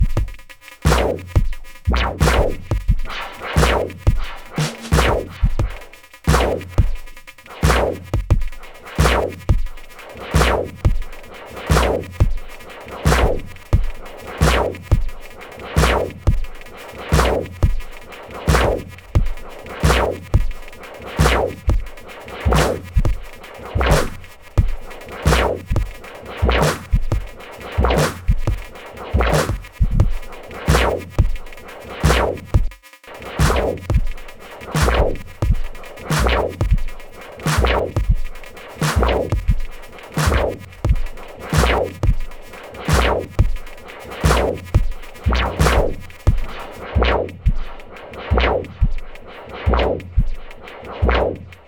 Two DT-patterns crossfaded in Live.